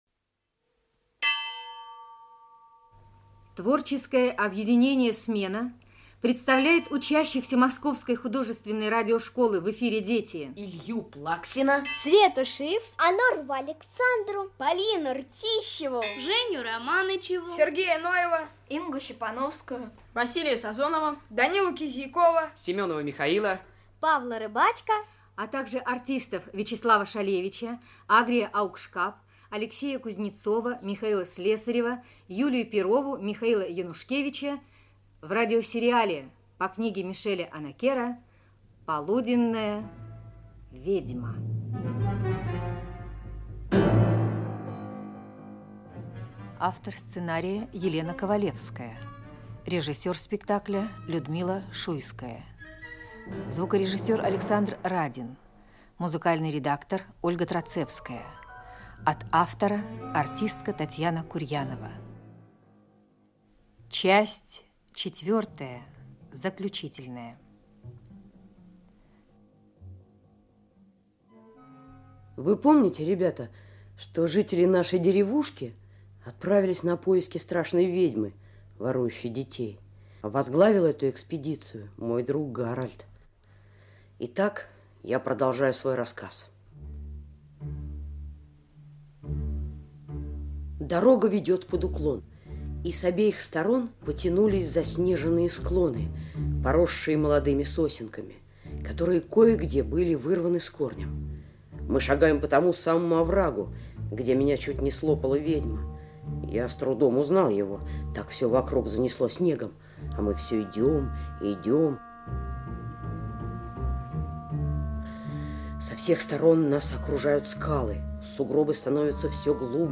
Аудиокнига Полуденная ведьма. Часть 4 | Библиотека аудиокниг
Часть 4 Автор Мишель Онакер Читает аудиокнигу Вячеслав Шалевич.